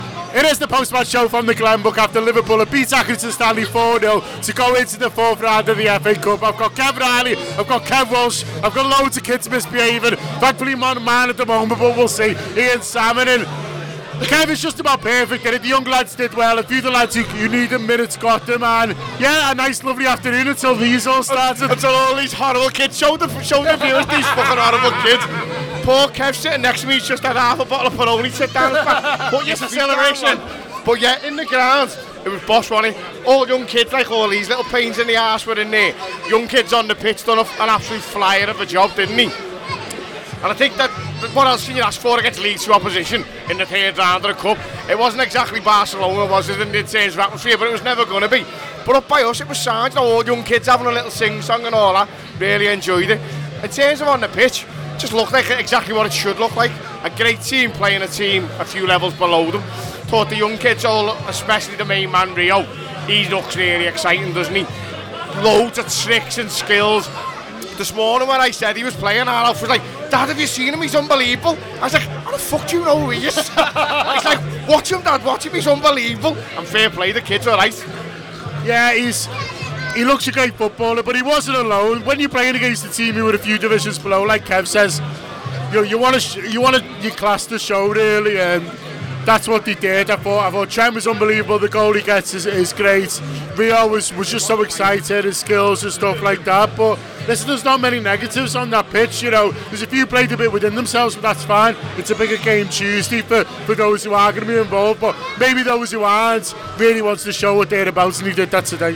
The Anfield Wrap’s post-match reaction podcast after Liverpool 4 Accrington Stanley 0 in the FA Cup third round at Anfield.